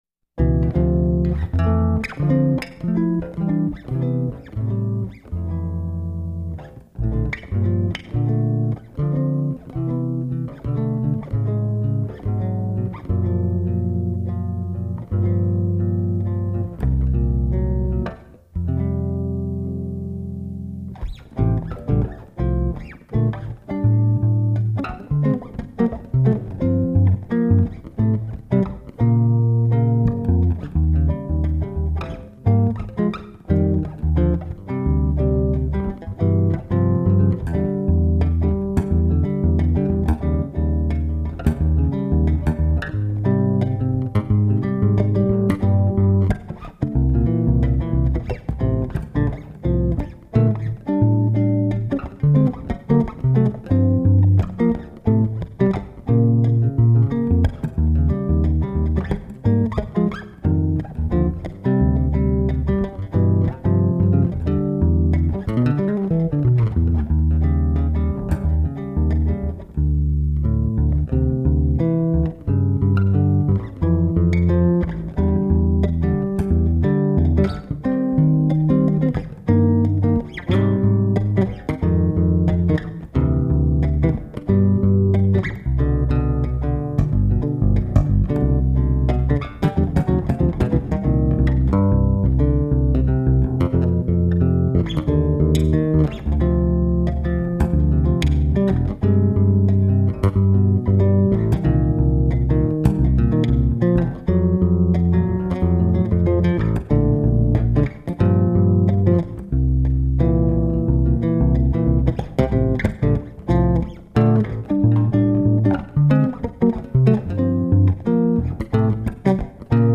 Genre  Jazz
Styles  Crossover Jazz